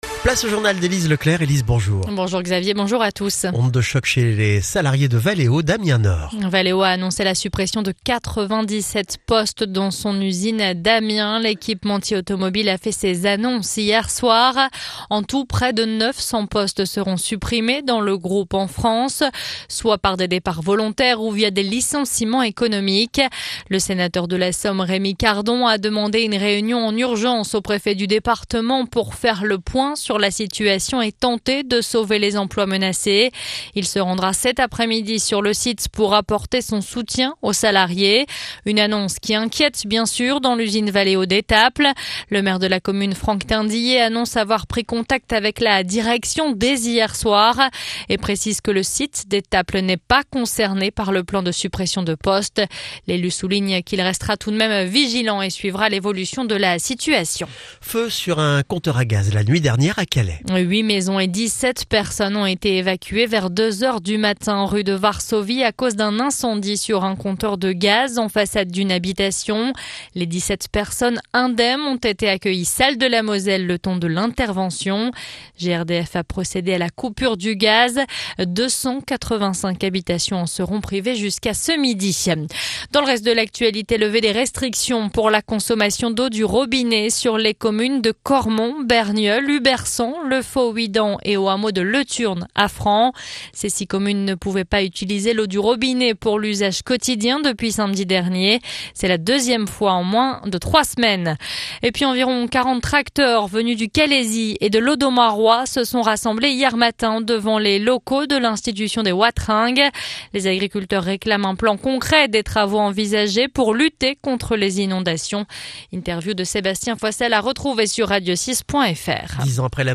Le journal du jeudi 28 novembre 2024